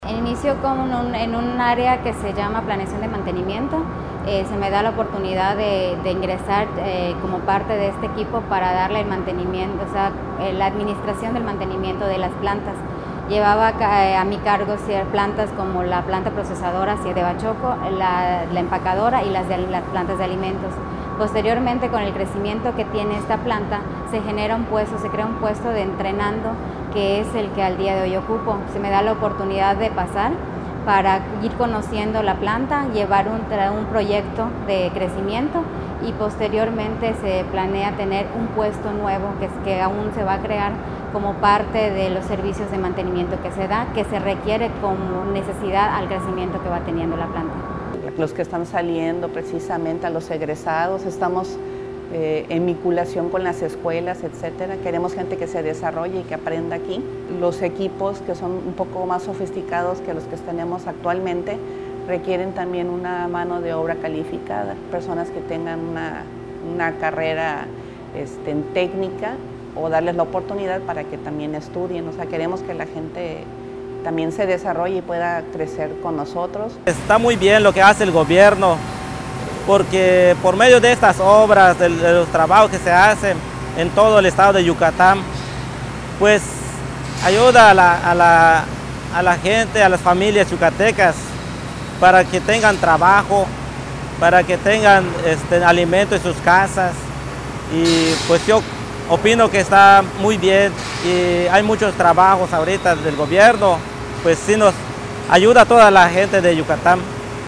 Audio: Entrevistas
AUDIO_ENTREVISTAS_EMPLEO.mp3